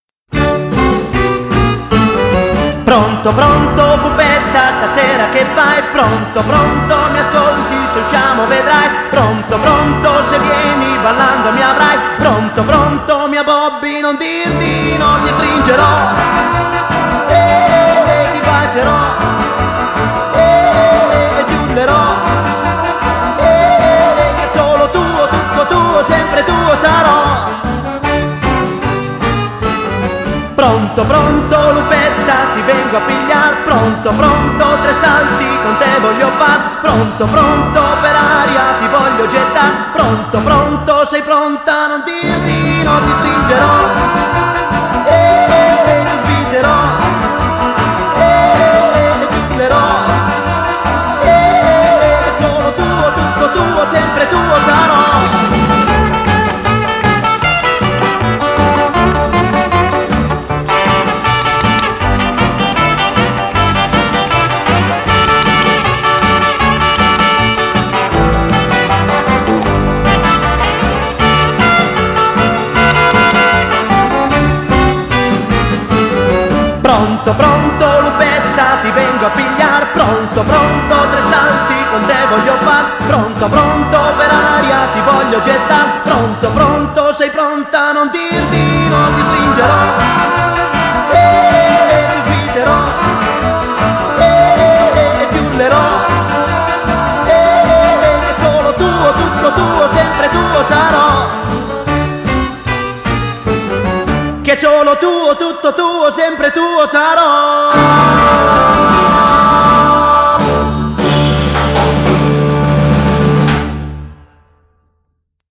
Genre: Pop, Jazz, Rock'n'roll